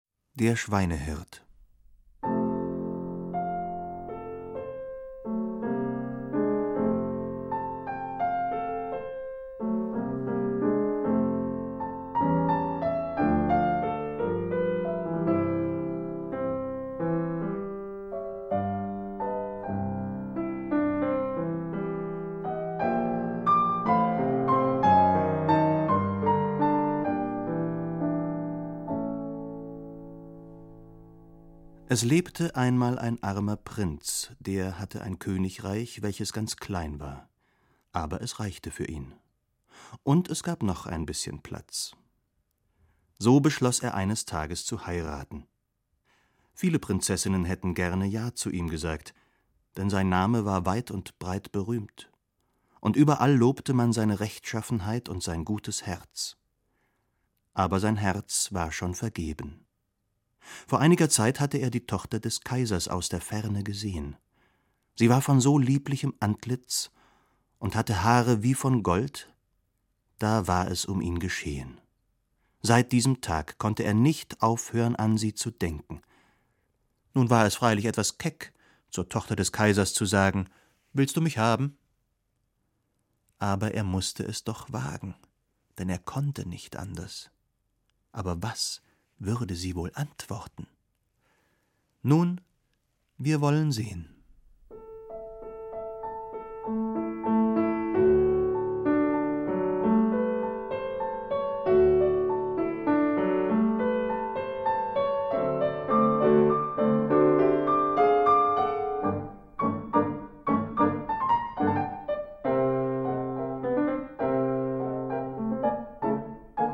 Klassische Musik und Sprache erzählen.
Musik von Carl Reinecke (1824-1910)
für Klavier zu vier Händen.
Klavierduo
Erzähler: Ulrich Noethen